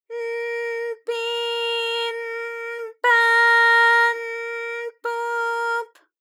ALYS-DB-001-JPN - First Japanese UTAU vocal library of ALYS.
p_n_pi_n_pa_n_pu_p.wav